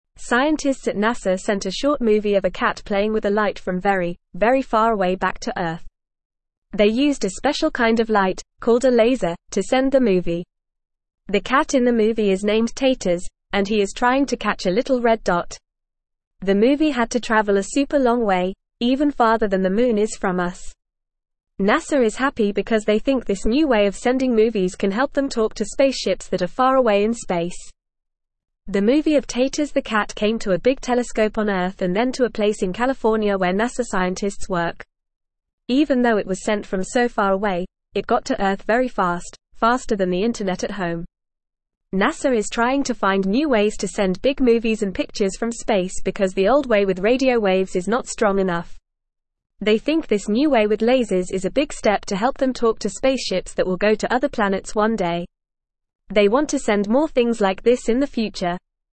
Fast
English-Newsroom-Beginner-FAST-Reading-NASA-Sends-Movie-of-Cat-Playing-with-Light-from-Space.mp3